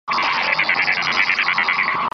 دانلود آهنگ رادیو 14 از افکت صوتی اشیاء
جلوه های صوتی
دانلود صدای رادیو 14 از ساعد نیوز با لینک مستقیم و کیفیت بالا